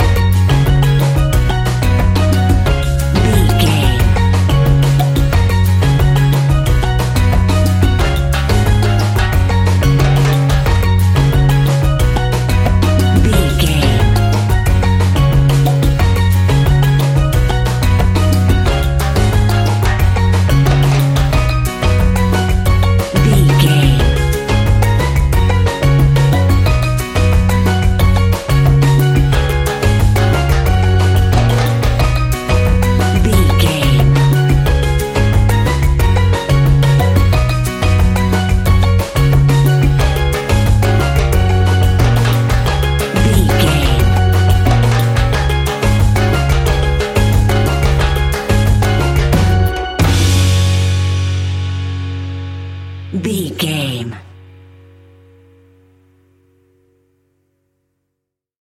Uplifting
Ionian/Major
E♭
steelpan
happy
drums
percussion
bass
brass
guitar